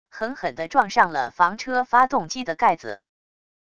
狠狠地撞上了房车发动机的盖子wav音频